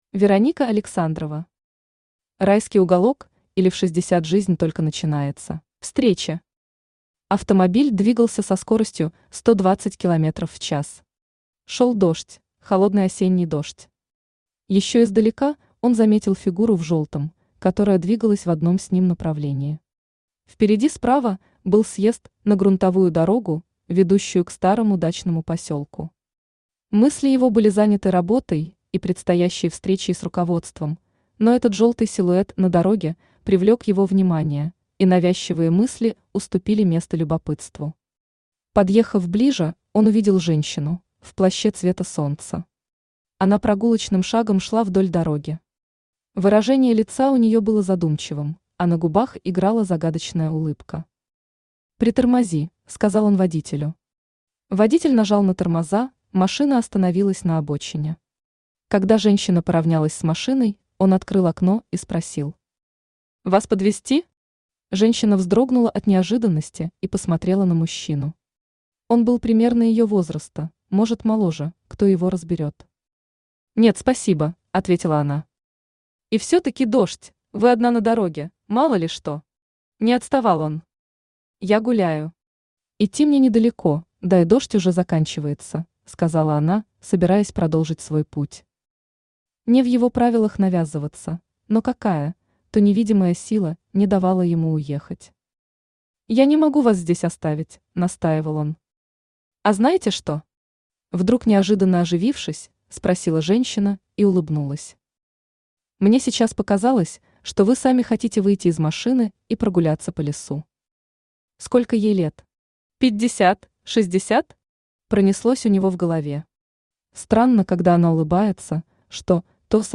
Аудиокнига Райский уголок, или В шестьдесят жизнь только начинается | Библиотека аудиокниг
Aудиокнига Райский уголок, или В шестьдесят жизнь только начинается Автор Вероника Александрова Читает аудиокнигу Авточтец ЛитРес.